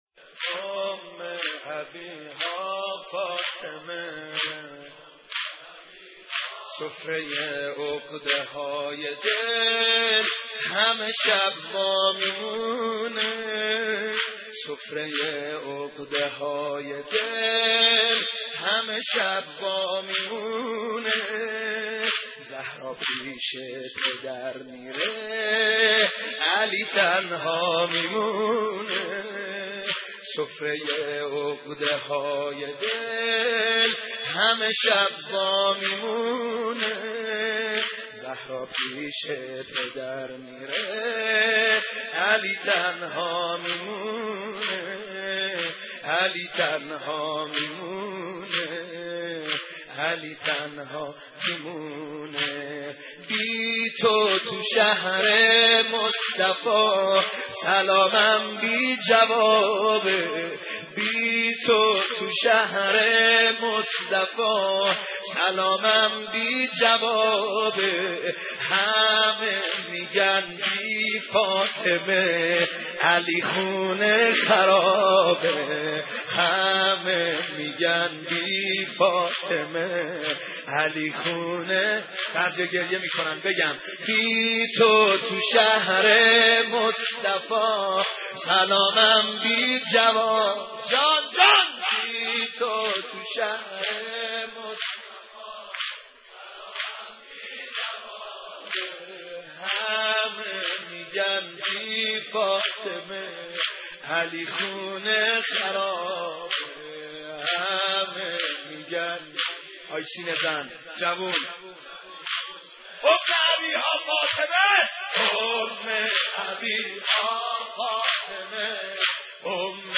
دانلود مداحی علی تنها می مونه - دانلود ریمیکس و آهنگ جدید
سینه زنی در شهادت حضرت فاطمه(س)